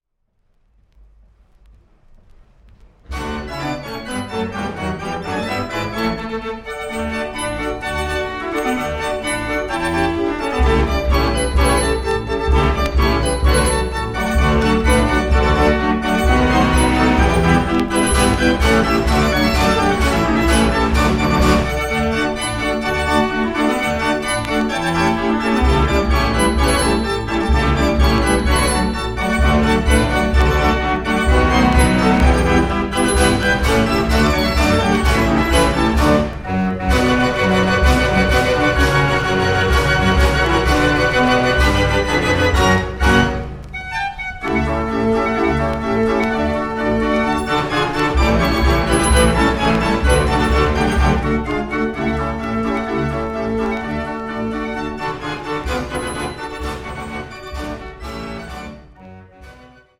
Formaat: Vinyl LP, Stereo & Mono
Stijl: Klassiek – in draaiorgelarrangement
Zijde B – Dansen, marsen en foxtrots